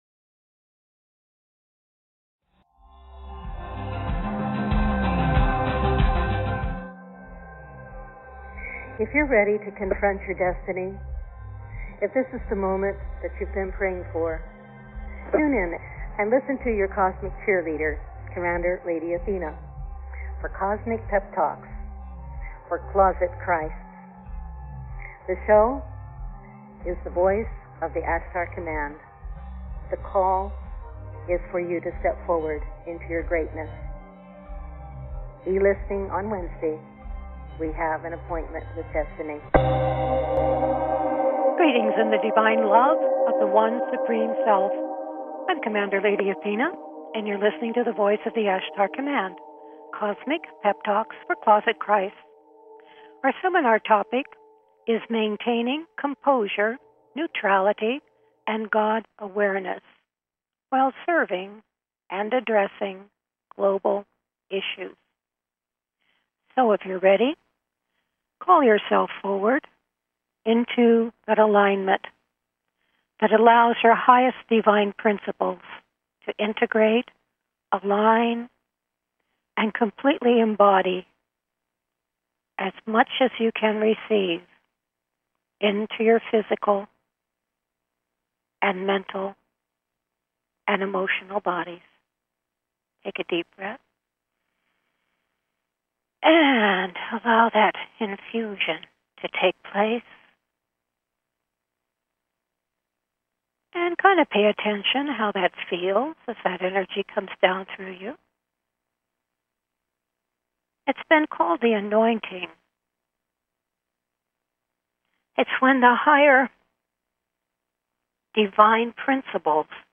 The Voice of the Ashtar Command, a seminar